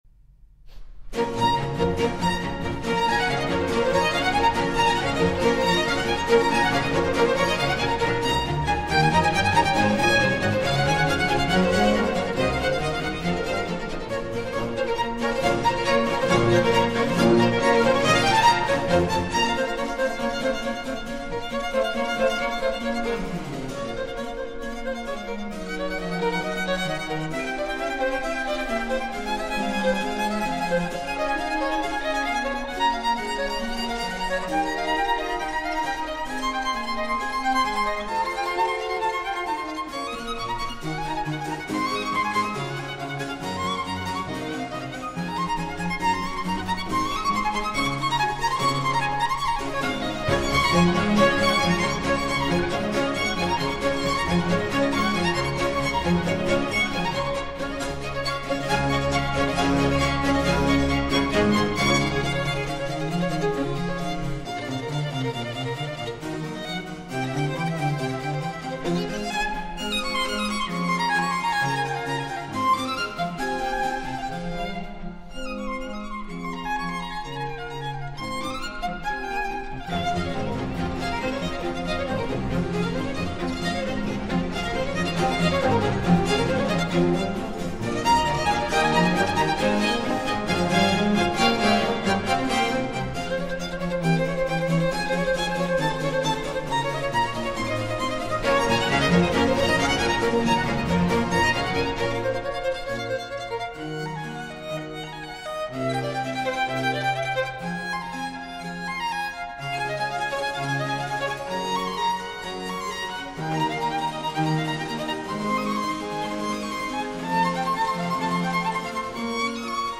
Violin Concerto in B Minor